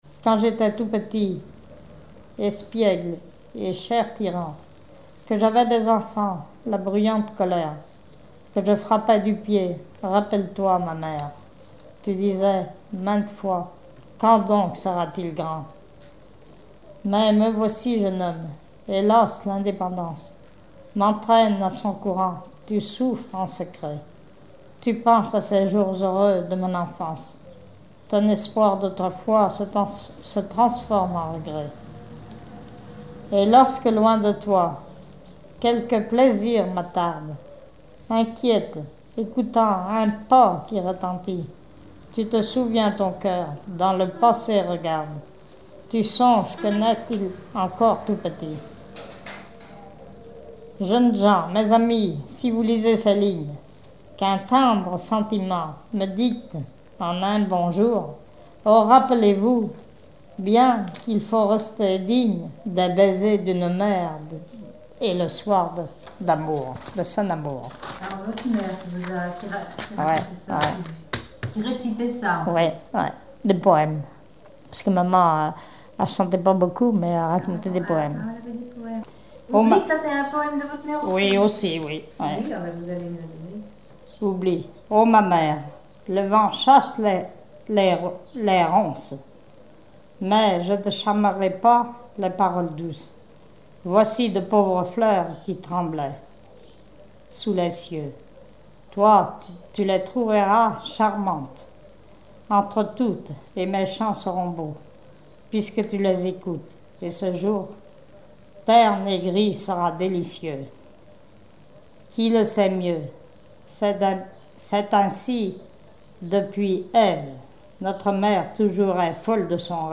Genre poésie
Enquête Arexcpo en Vendée-Association Joyeux Vendéens
Catégorie Récit